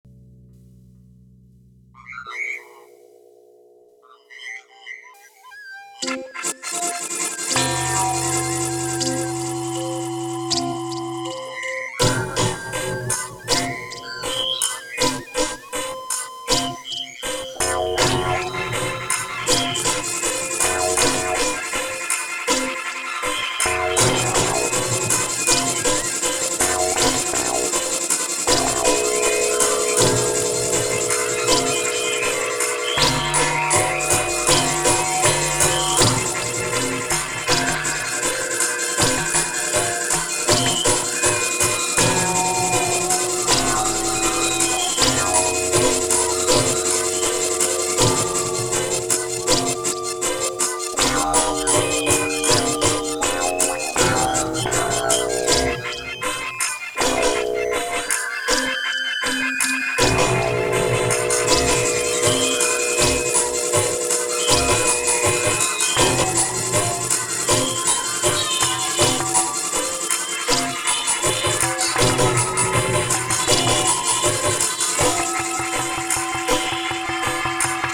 This interactive sculptural project is comprised of nineteen unique mouth-blown glass bottle forms, brass tubes, tequila, whiskey, inkjet on Tyvek coated paper with contemporary "looping" sound compositions. The sound component is based on the background music/auxiliary din [a synthesized do-pe-doop, pe-doop] of early video games such as Club Penguin and Tetris.